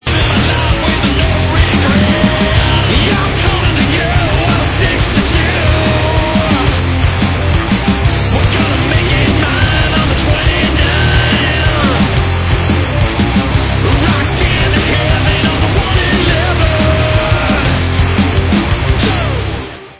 MONSTER TRUCK RIFFS
sledovat novinky v kategorii Rock